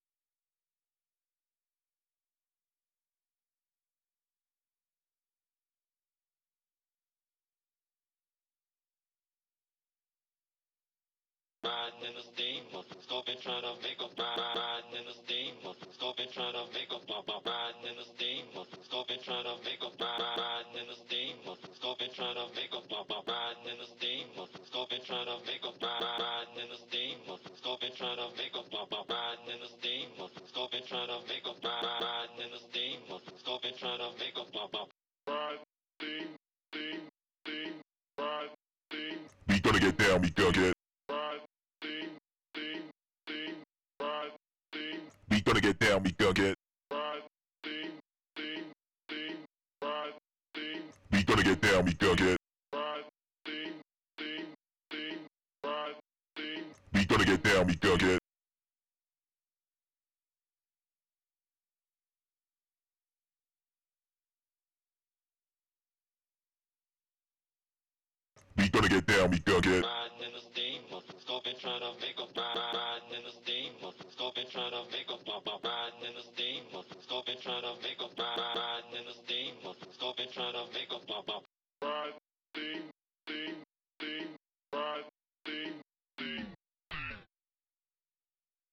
south 165 vocal samples.wav